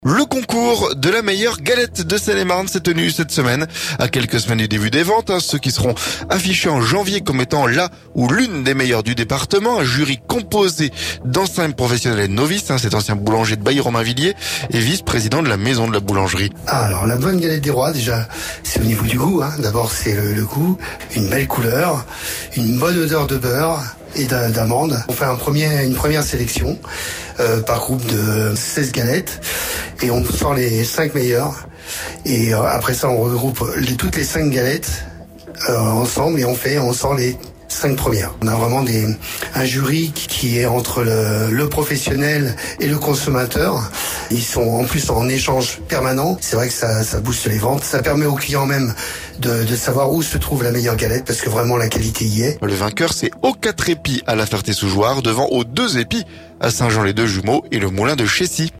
REPORTAGE - Comment est élue la meilleure galette de Seine-et-Marne?